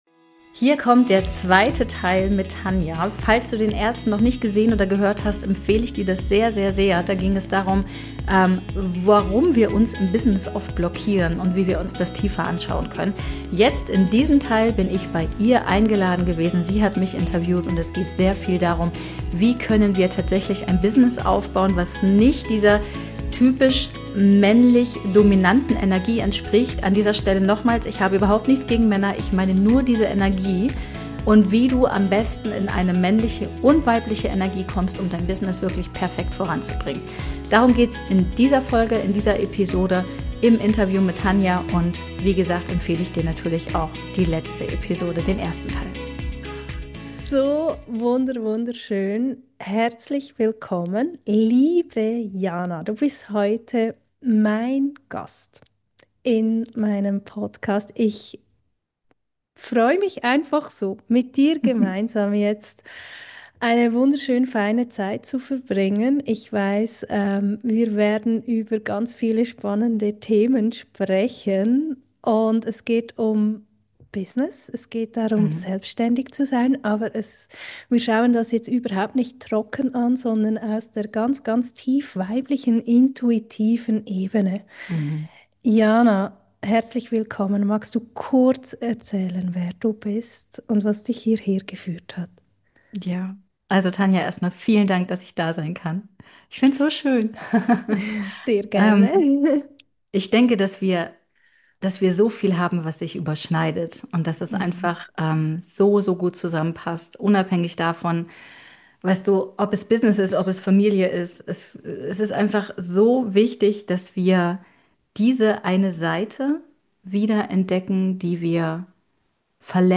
Hier kommt Teil 2 unseres wundervollen gegenseitigen Interviews. Es geht um Intuition, Gefühle und deine ganz eigene Superpower für dein Business.